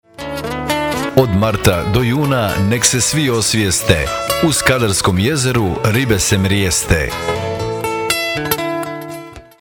Dzingl-SKADARSKO-JEZERO-ZABRAN.mp3